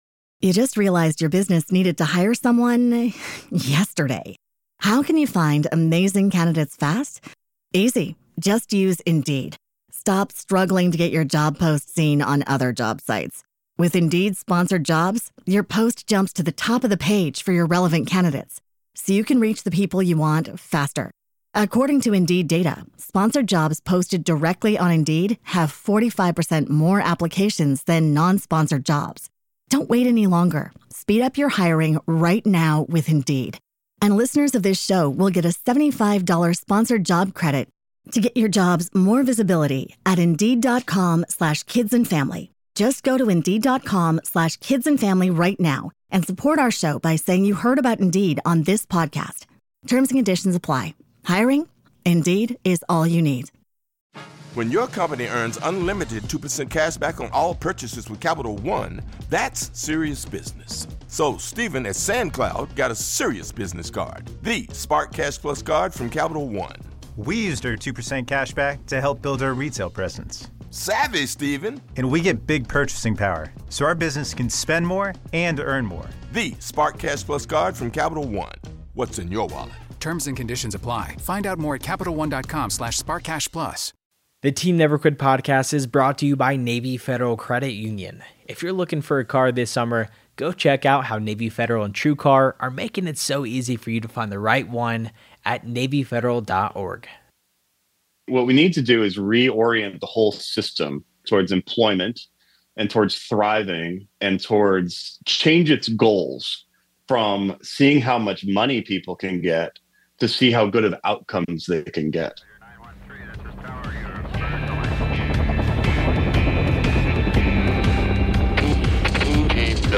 This conversation goes beyond the battlefield to explore themes of honor, duty, and the character that defines true greatness.